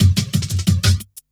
17 LOOP10 -R.wav